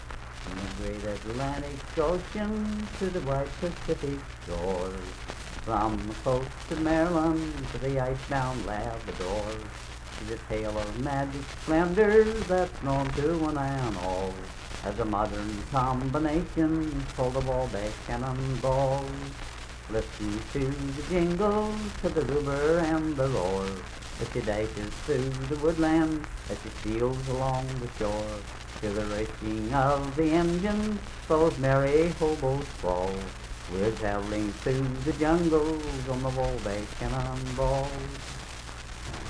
Unaccompanied vocal music
Voice (sung)
Clay (W. Va.), Clay County (W. Va.)